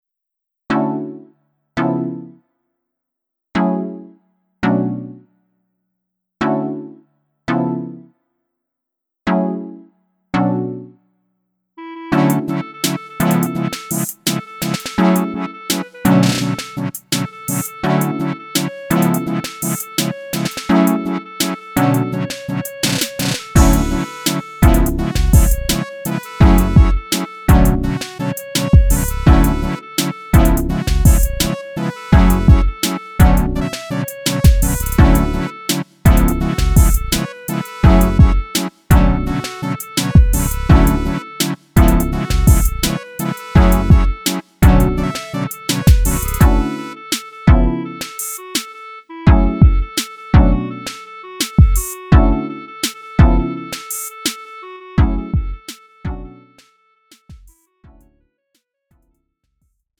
음정 -1키 3:31
장르 가요 구분 Lite MR